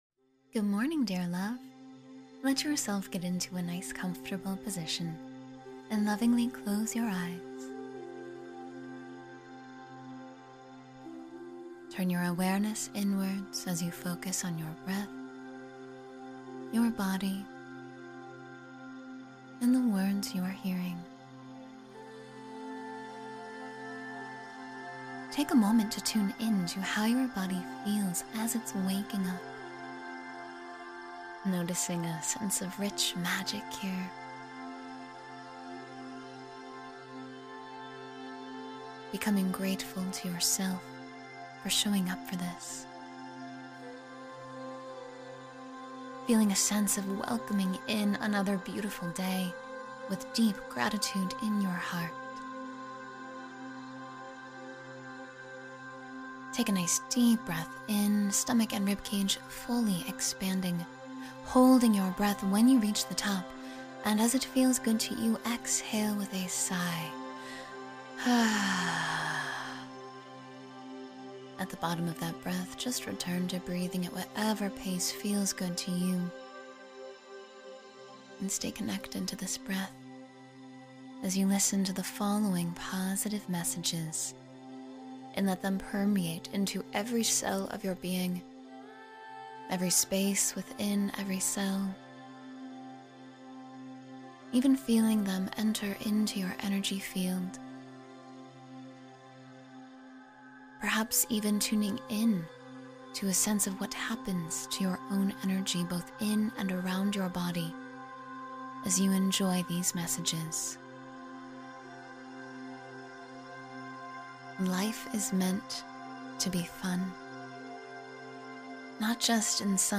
A Morning Meditation of Empowered Presence — Step Into Your Power